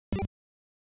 systembrowse.wav